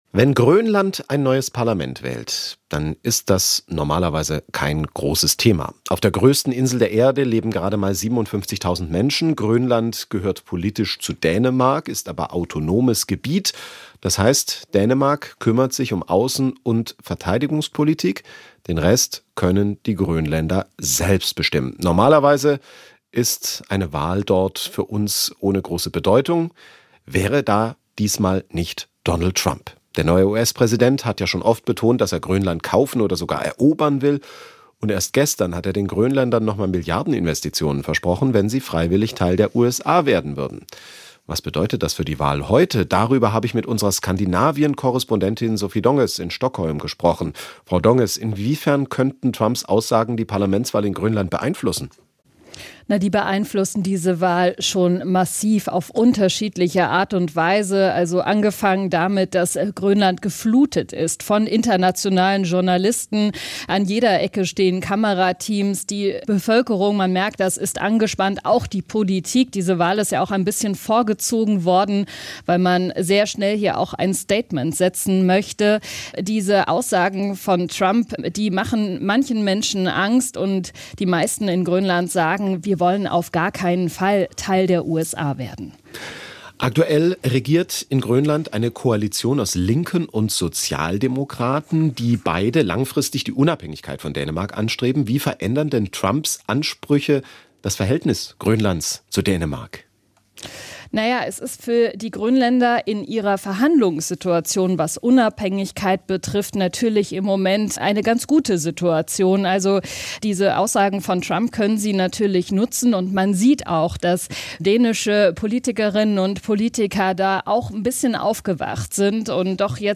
Topthemen aus Politik, Wirtschaft, Wissenschaft und Sport: Wir ordnen ein, wir klären auf, wir bohren nach. "SWR Aktuell Im Gespräch" - das sind Interviews mit Menschen, die etwas zu sagen haben.